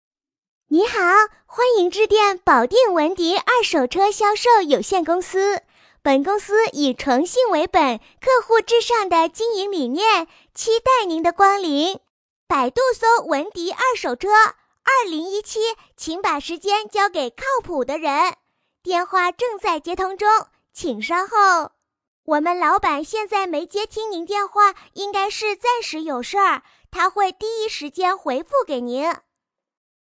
【女90号童音】童声彩铃
【女90号童音】童声彩铃.mp3